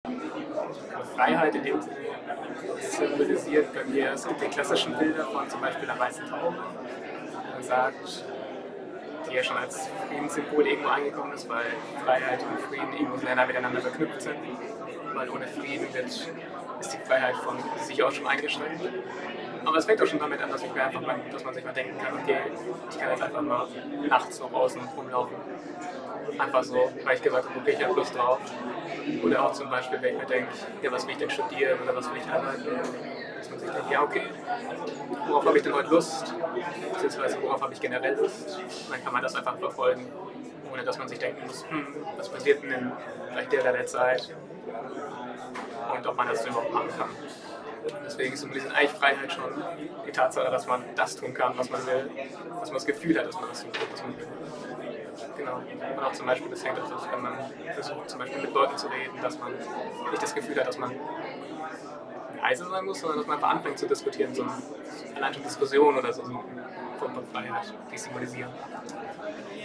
Standort der Erzählbox:
FALLING WALLS 2024 @ Falling Walls Science House, Berlin